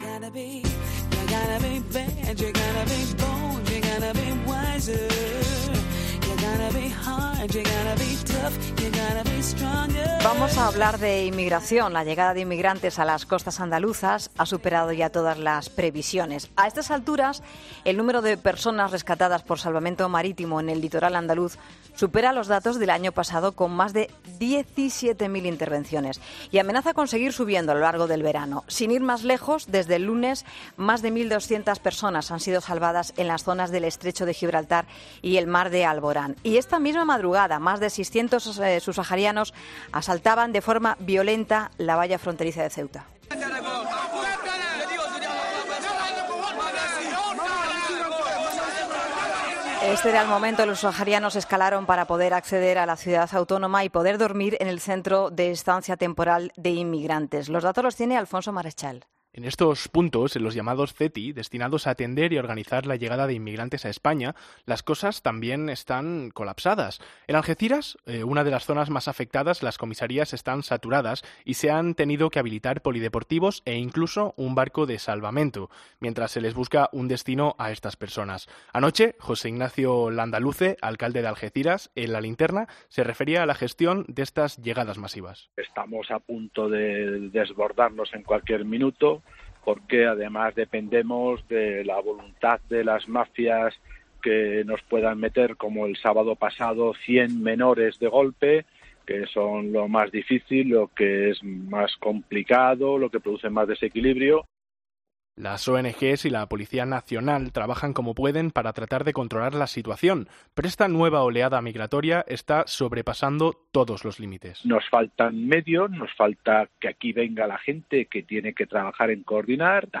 Hablamos en 'La Tarde' con Alfonso Rodríguez, delegado del Gobierno en Andalucía, sobre la llegada de inmigrantes a las costas andaluzas